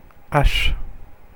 Ääntäminen
Ääntäminen France (Paris): IPA: [aʃ] Haettu sana löytyi näillä lähdekielillä: ranska Käännöksiä ei löytynyt valitulle kohdekielelle.